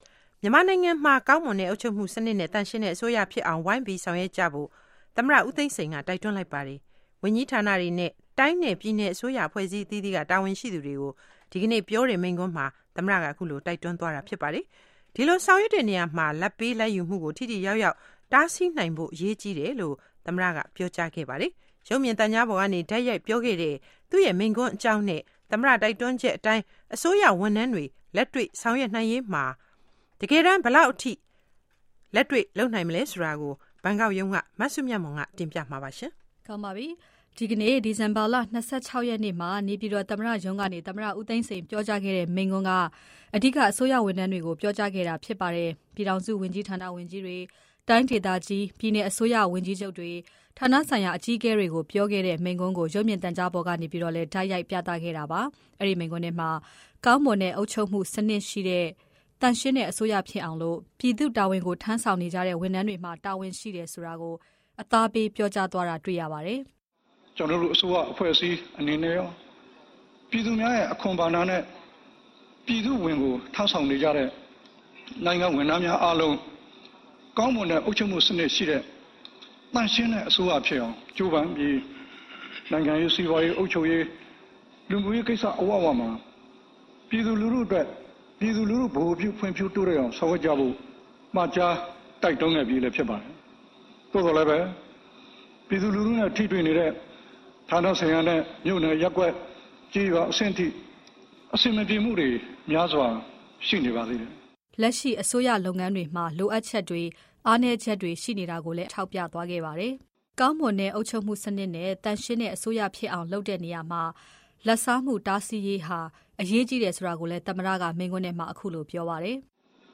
သမ္မတဦးသိန်းစိန် မိန့်ခွန်း။